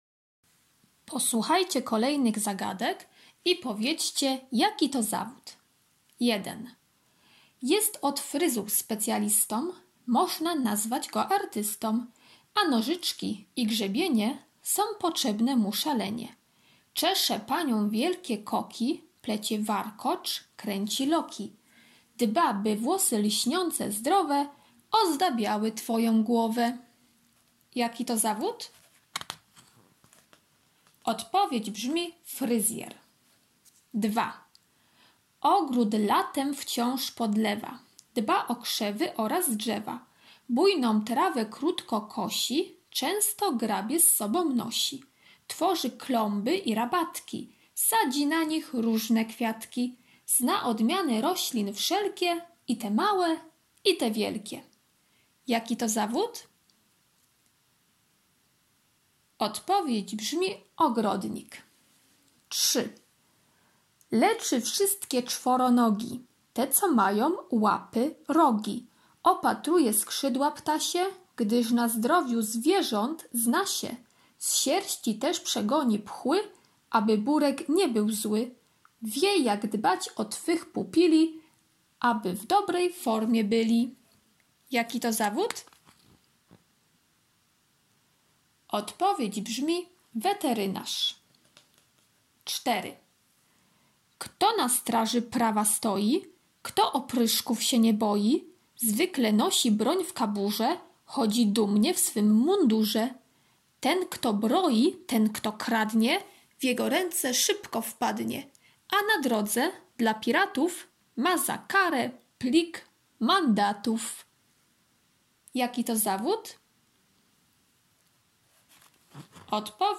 piątek - zagadki cz. 2 [8.88 MB] piątek - karta pracy nr 1 "Zawody" [219.09 kB] piątek - ćw. dla chętnych - litera L, l [105.14 kB]